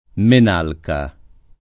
vai all'elenco alfabetico delle voci ingrandisci il carattere 100% rimpicciolisci il carattere stampa invia tramite posta elettronica codividi su Facebook Menalca [ men # lka ] pers. m. — personaggio della poesia bucolica